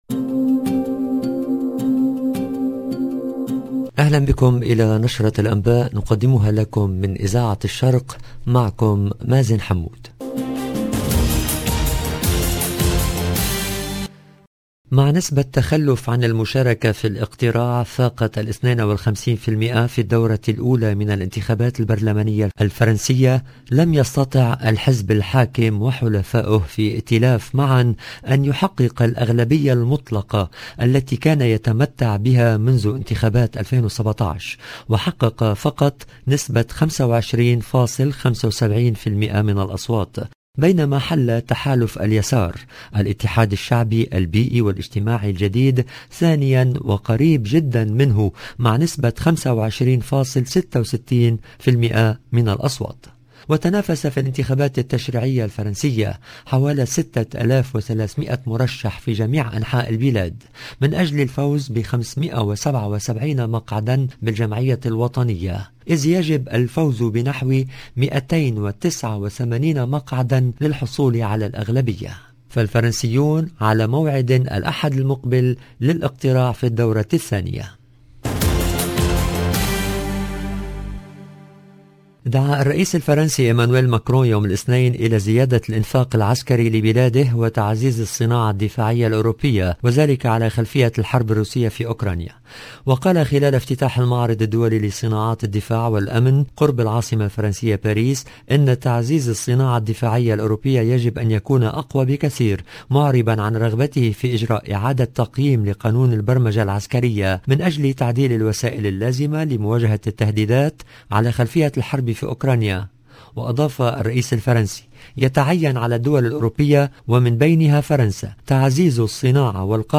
LE JOURNAL DU SOIR EN LANGUE ARABE DU 13/06/22